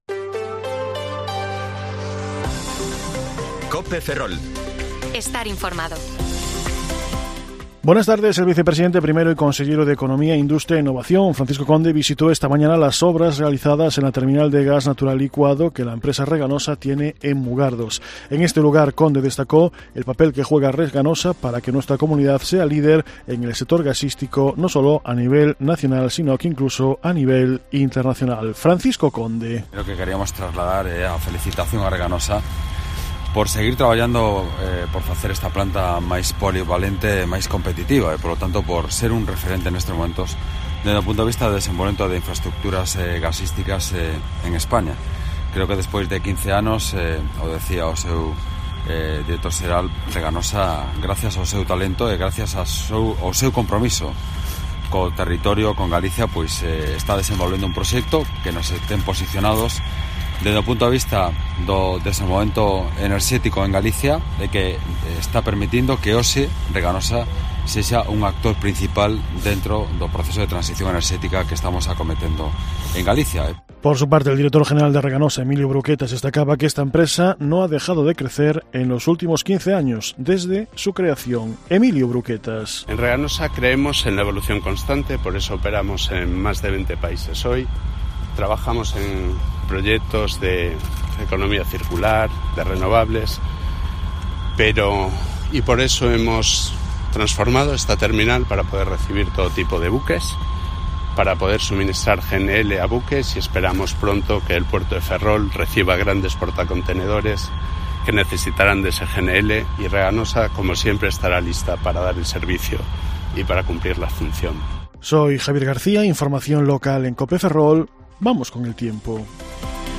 Informativo Mediodía COPE Ferrol 27/12/2022 (De 14,20 a 14,30 horas)